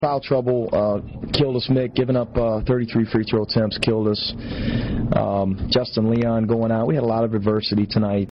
In his post-game press conference, Florida head coach Mike White discussed the strong defensive effort put forth by the Commodores in their home arena.